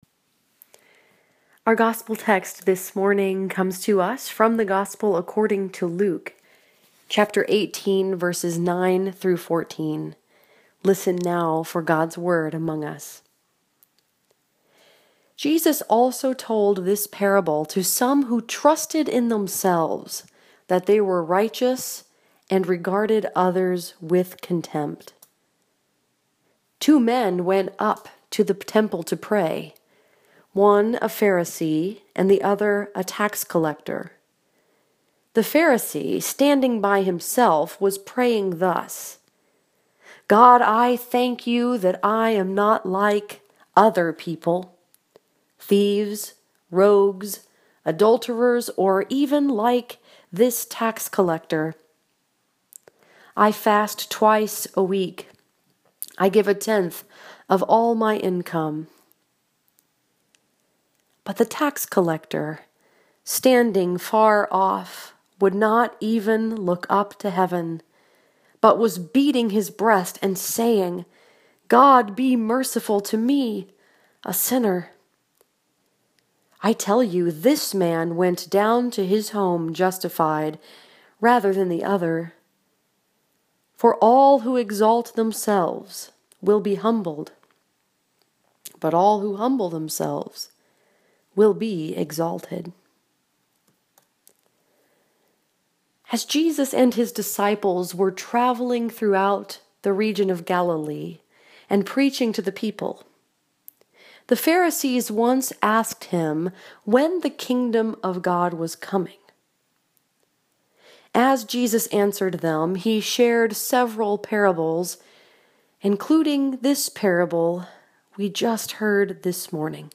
This sermon was preached at Northbrook Presbyterian Church in Beverly Hills, Michigan and was focused upon Luke 18:9-14.